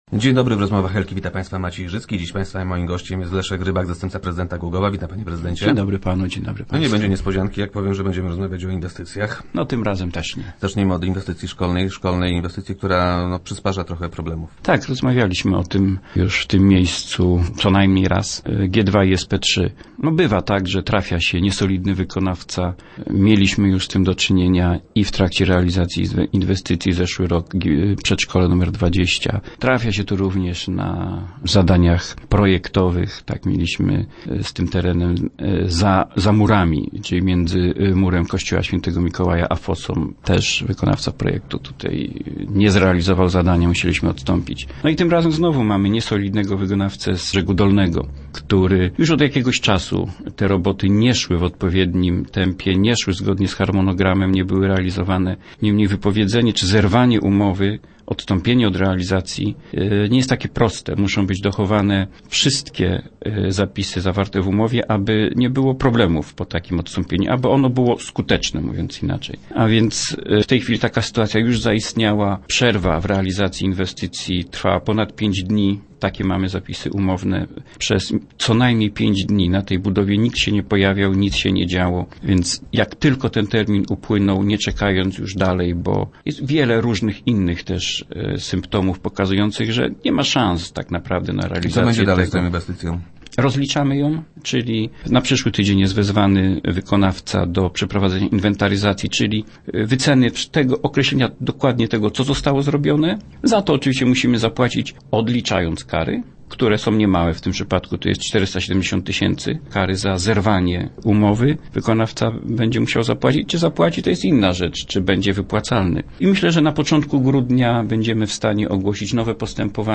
Negocjacje prowadziliśmy też w tym roku – twierdzi Leszek Rybak, zastępca prezydenta Głogowa, który był gościem Rozmów Elki.